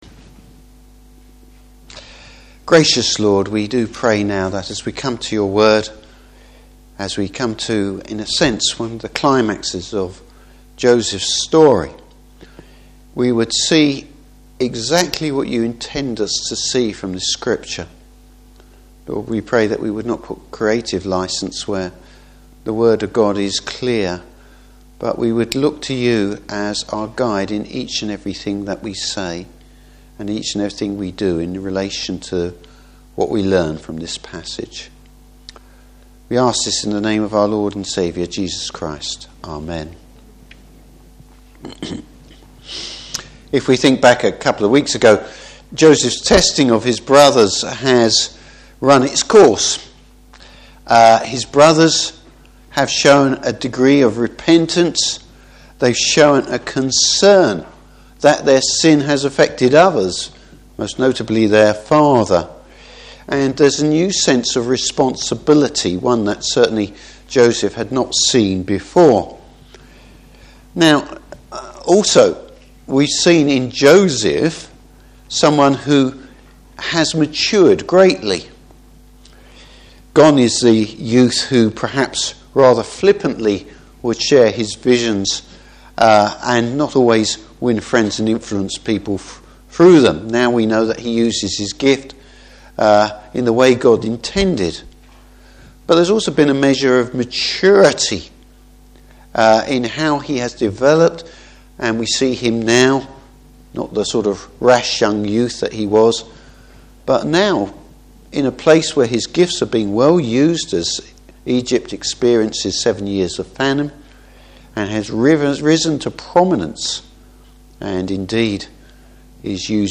Service Type: Evening Service Joseph reveals himself to his brothers.